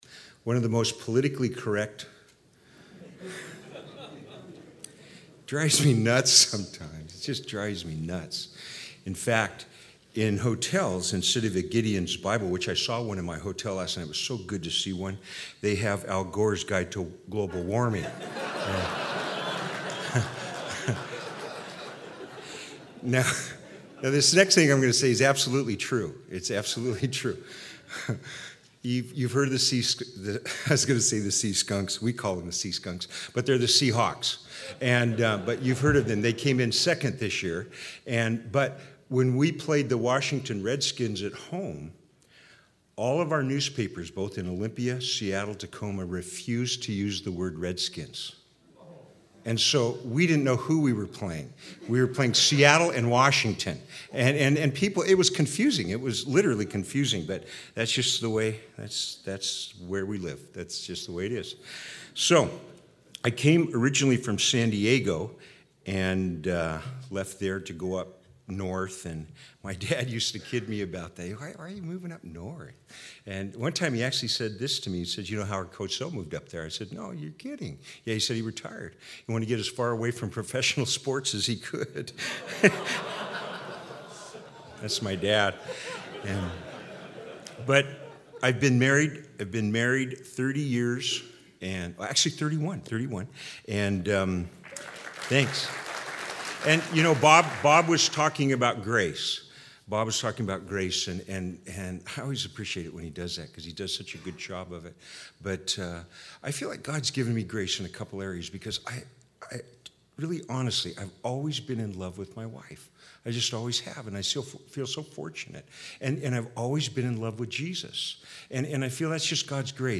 2006 DSPC Conference: Pastors & Leaders Date
2006 Home » Sermons » Session 2 Share Facebook Twitter LinkedIn Email Topics